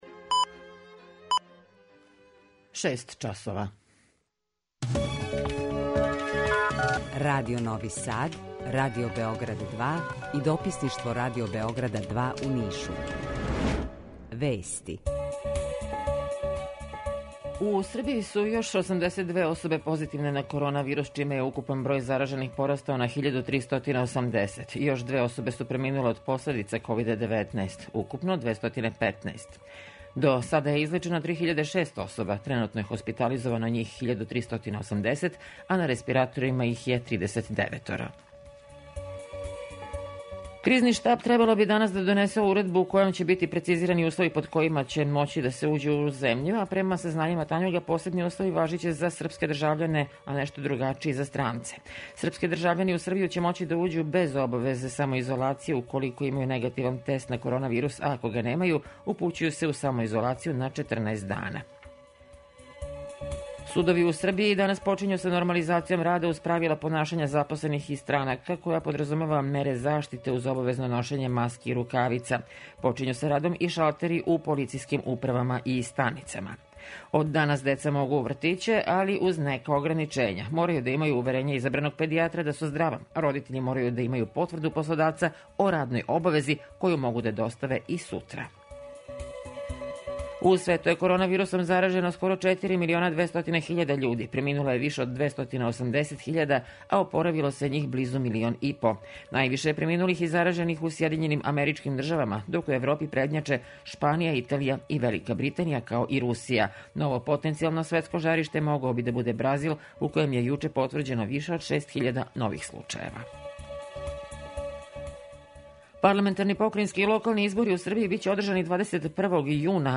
Јутарњи програм из три студија